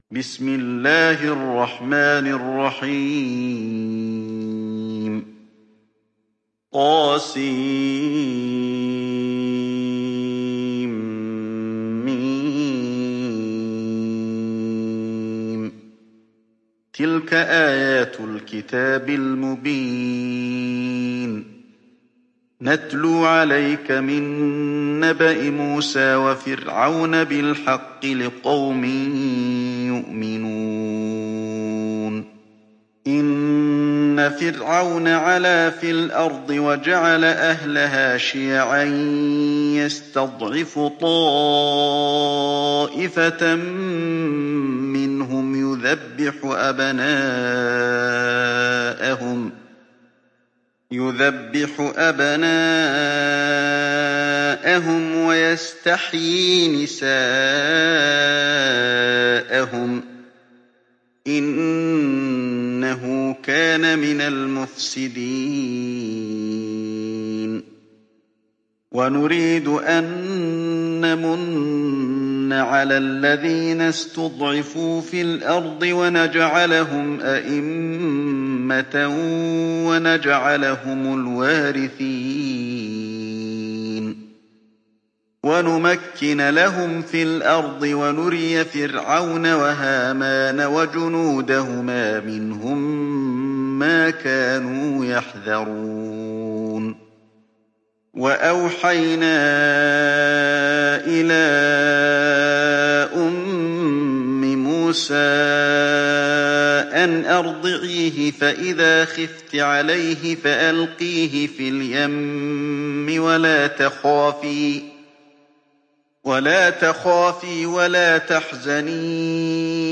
تحميل سورة القصص mp3 بصوت علي الحذيفي برواية حفص عن عاصم, تحميل استماع القرآن الكريم على الجوال mp3 كاملا بروابط مباشرة وسريعة